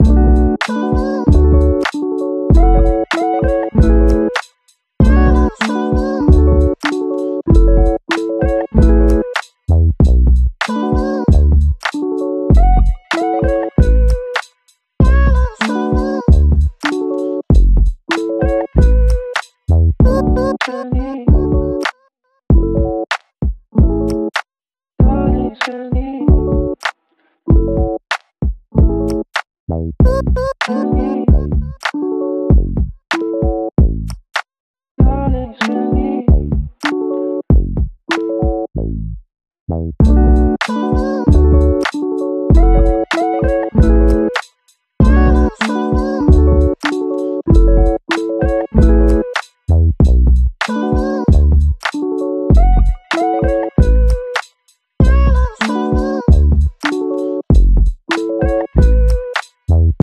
Sounds of the camper! 😎 sound effects free download